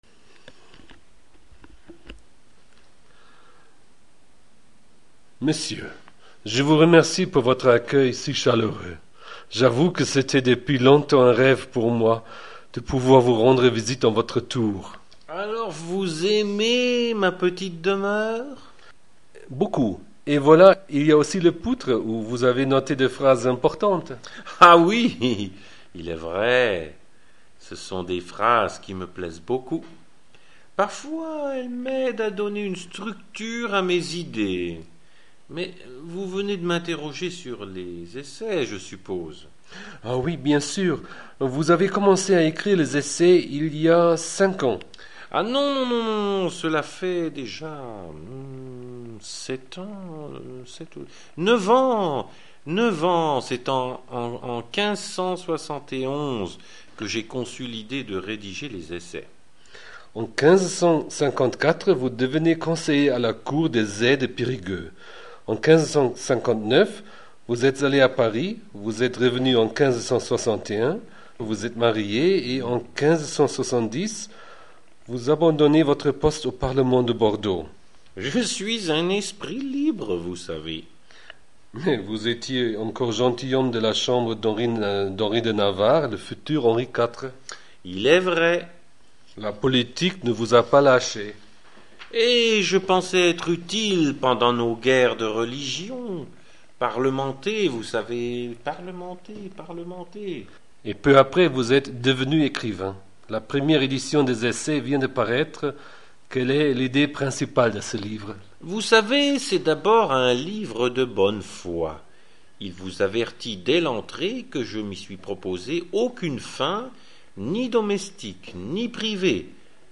Und schließlich habe ich ihn ausführlich über das Kapitel > De l’institution des enfans befragt, in dem es um die Erziehung geht. Zögern Sie nicht, das Interview zu hören und den Text – mit meiner schnellen Mitschrift – zu lesen.